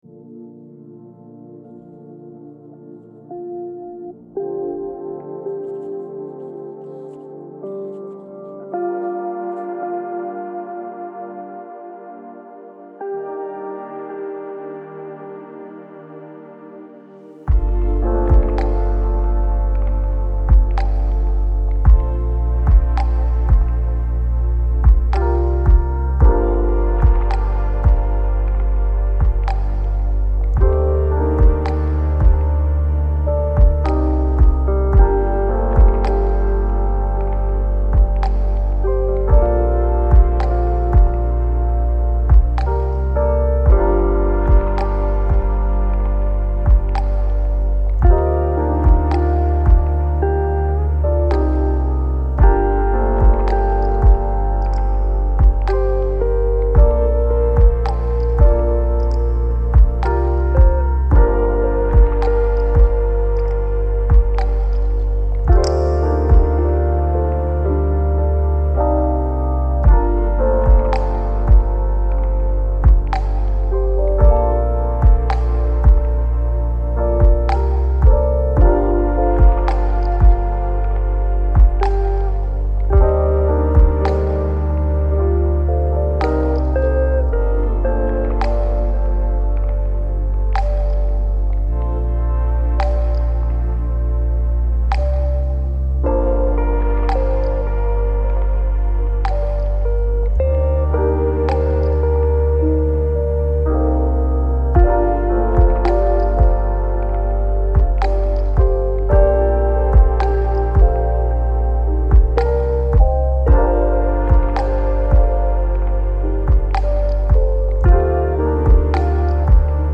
Pluie Calme : Ambiance Étude